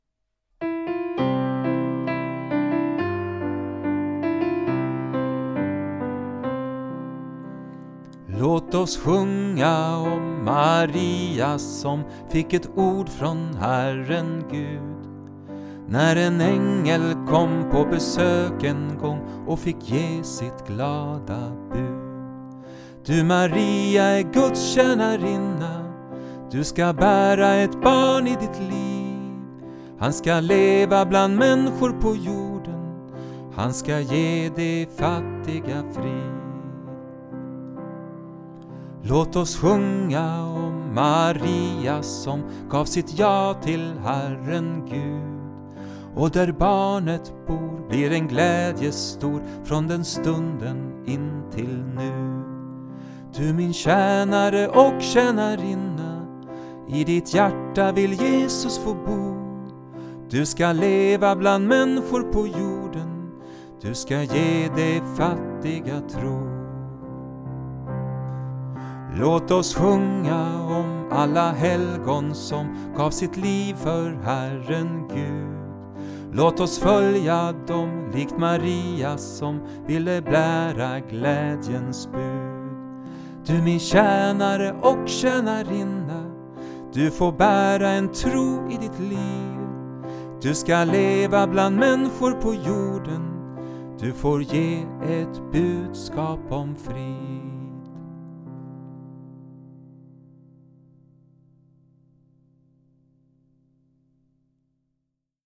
Här som solo.